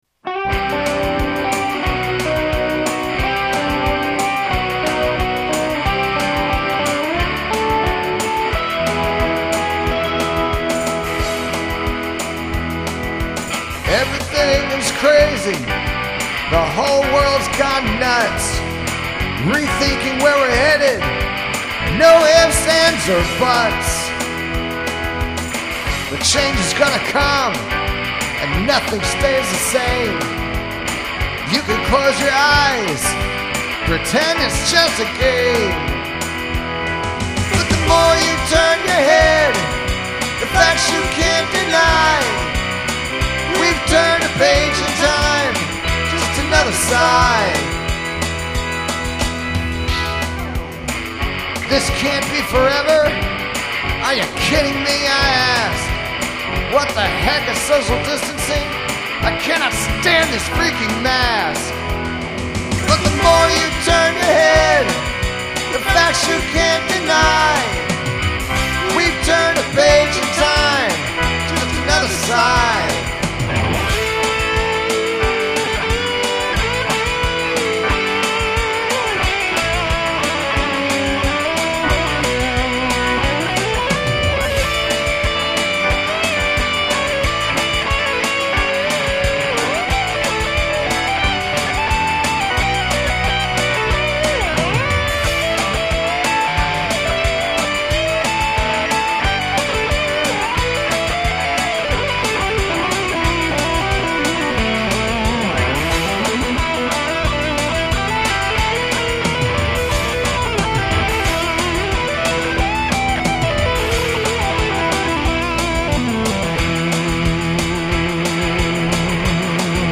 180 b/m 6/25/20